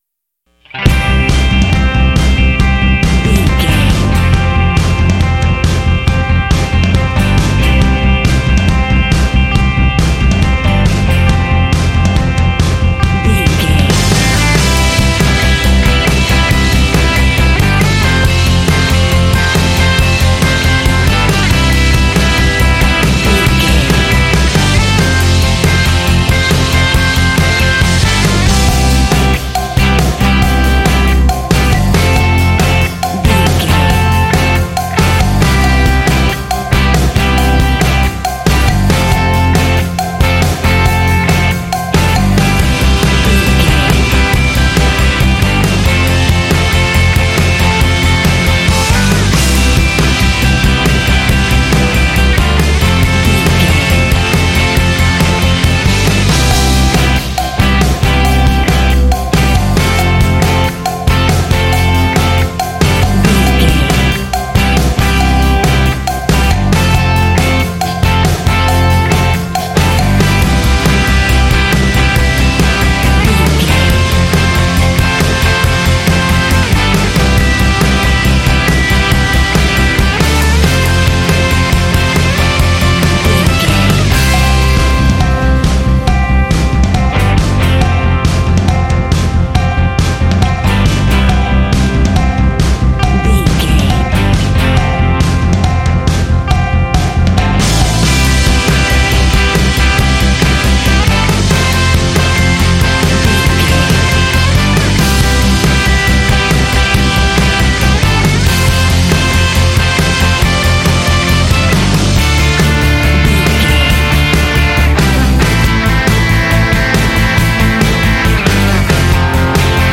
Ionian/Major
energetic
uplifting
drums
electric guitar
bass guitar